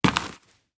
Light  Dirt footsteps 3.wav